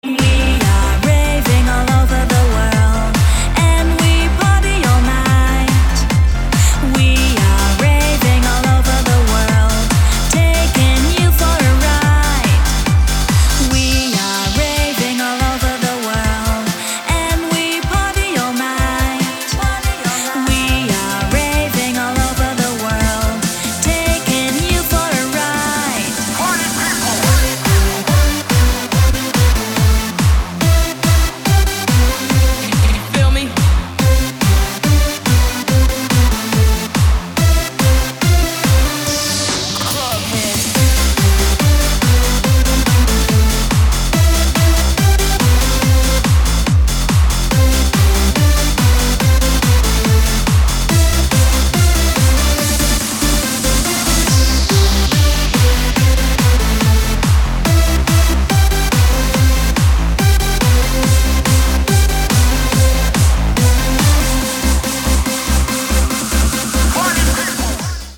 • Качество: 224, Stereo
мужской голос
громкие
женский вокал
dance
Electronic
электронная музыка
club
Eurodance
танцевальные